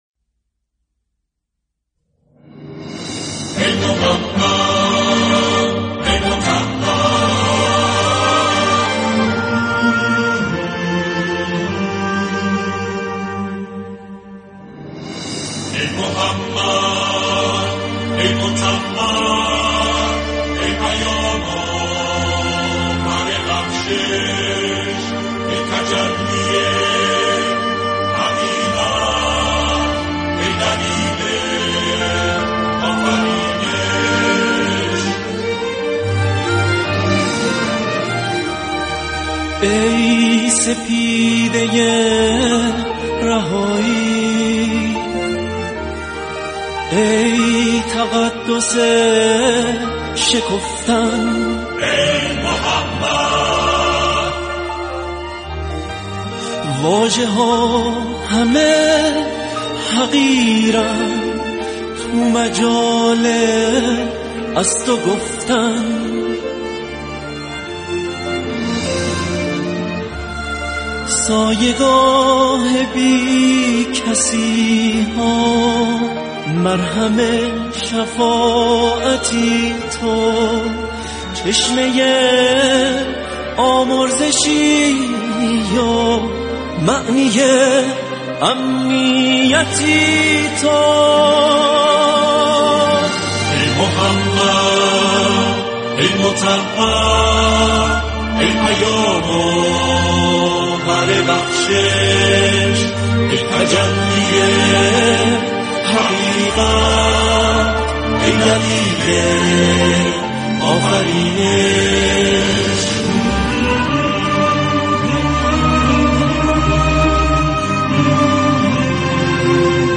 سرودهای ۱۷ ربیع الاول